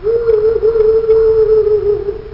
Barn Owl Sound Effect
Download a high-quality barn owl sound effect.
barn-owl.mp3